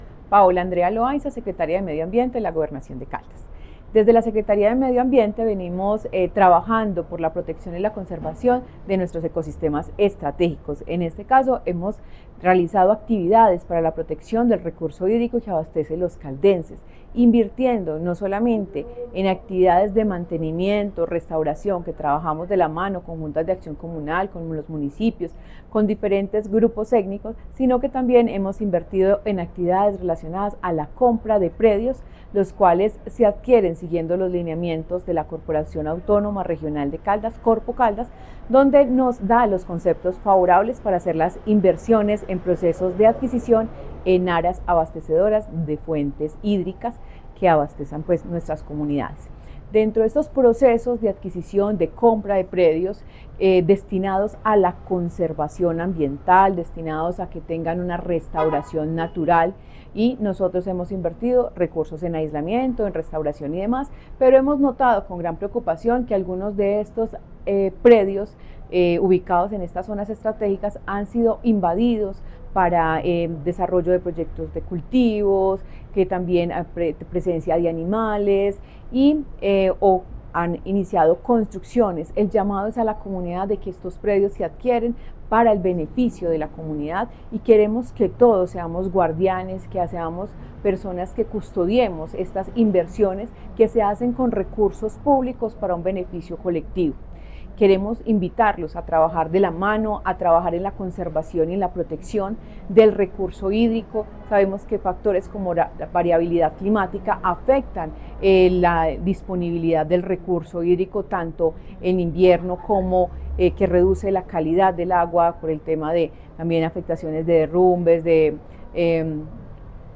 Secretaria de Medio Ambiente, Paola Andrea Loaiza Cruz.